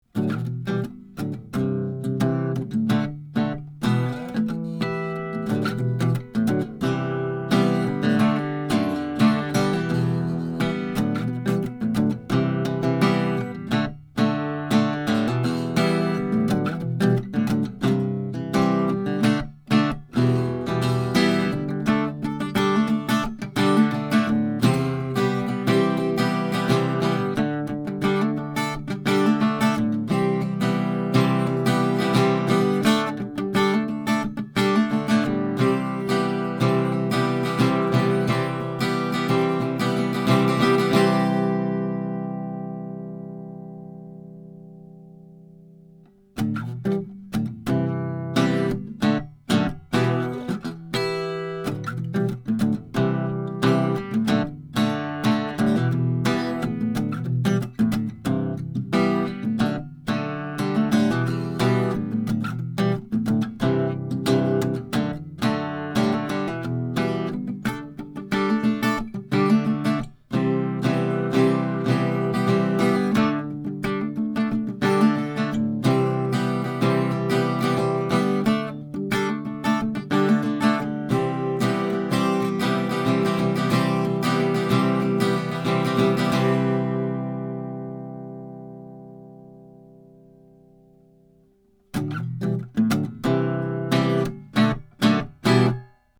The sound is big, with some nice bass heft, and the trebles are smooth and silky.
These 16 MP3s are recorded through a spaced pair of Schoeps CMT 541 condenser mics into a pair Black Lion Audio B173 premps using a Metric Halo ULN8 interface, with MP3s made in Logic. These files have no compression, EQ or reverb -- just straight signal.
1933 GIBSON L10 ARCHTOP
L10CMT541sBLAHarmonic.mp3